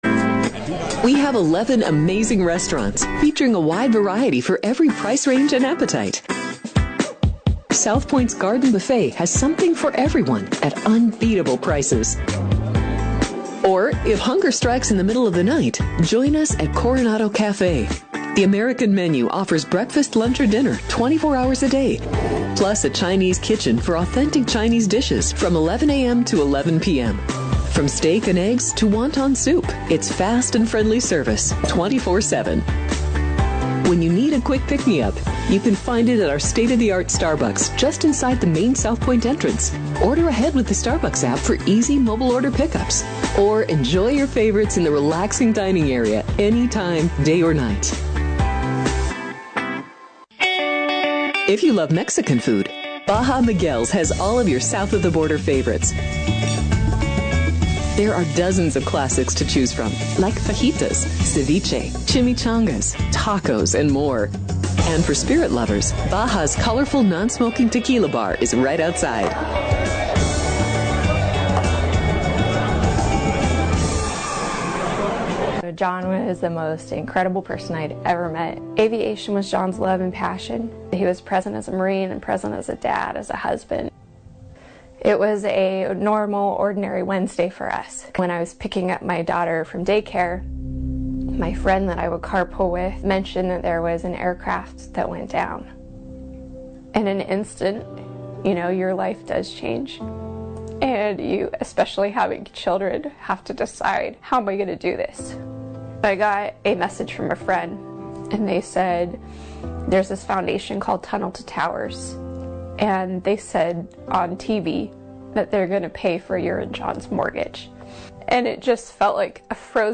Radio Program